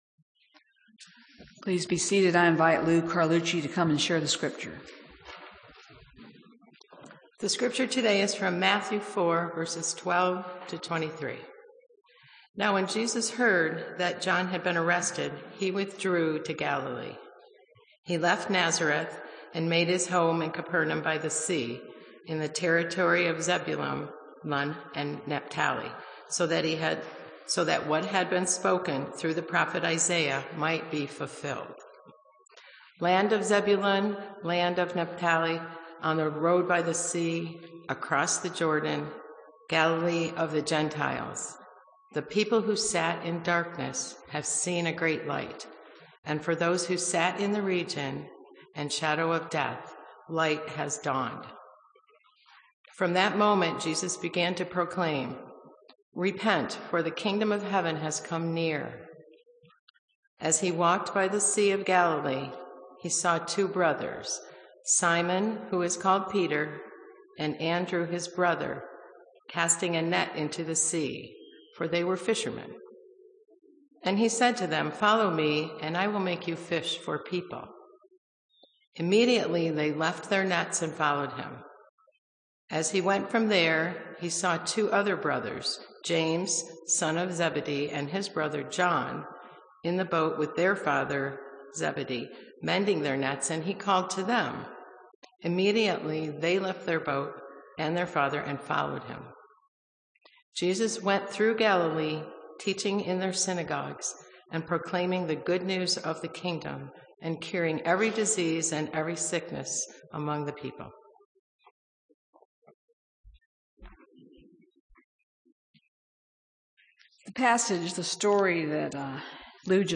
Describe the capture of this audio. Simply click on the date and title below to hear an audio recording of that week’s Scripture and meditation.